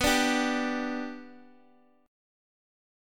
Bm#5 chord